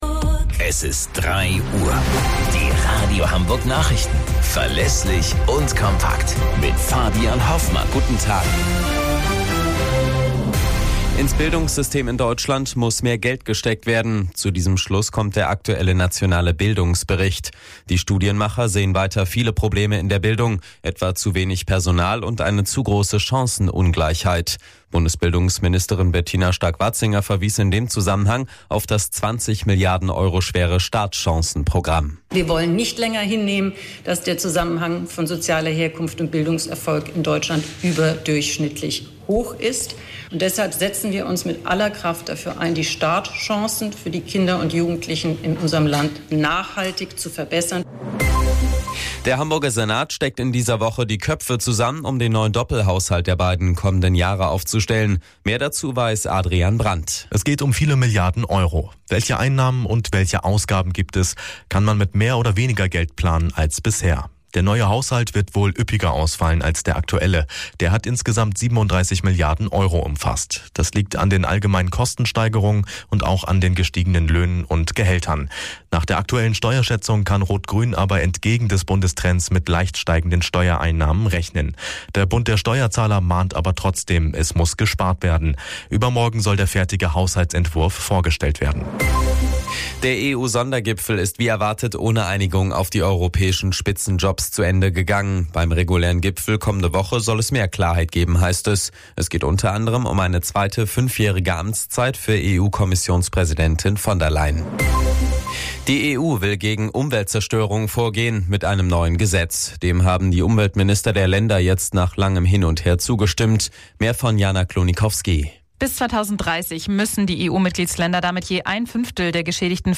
Radio Hamburg Nachrichten vom 18.06.2024 um 05 Uhr - 18.06.2024